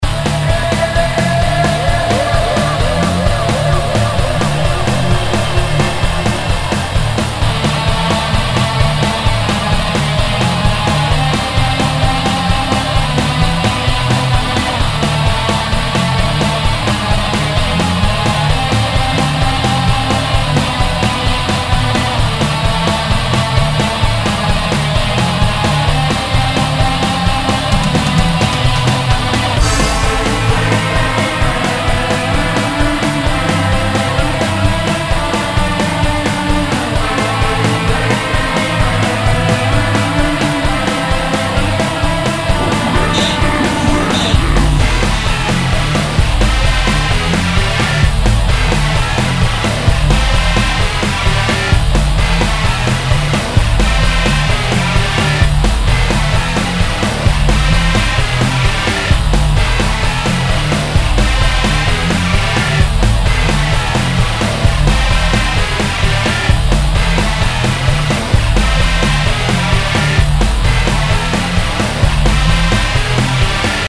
industrial?